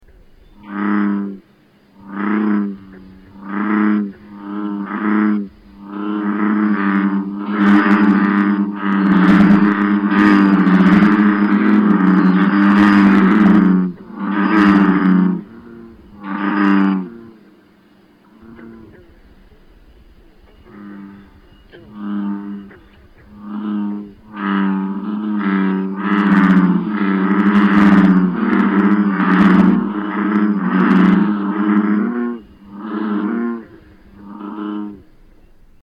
Bullfrog